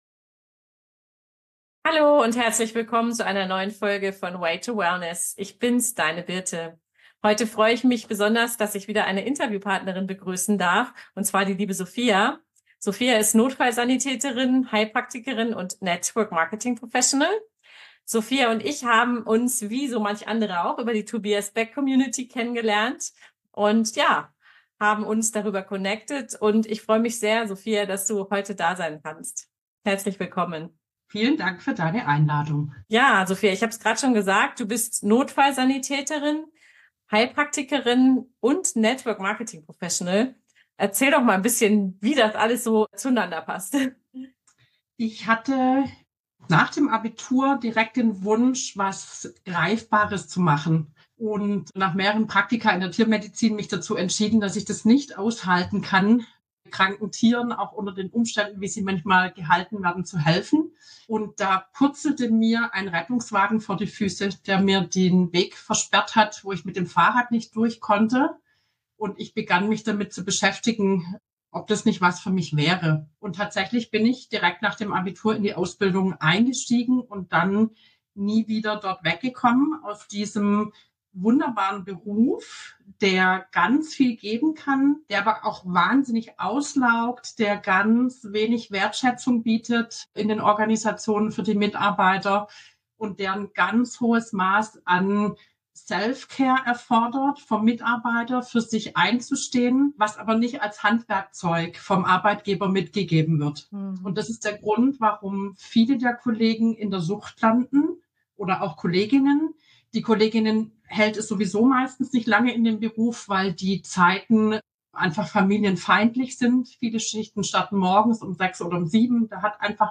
Zwischen Rettungsdienst und Selbstständigkeit – Interview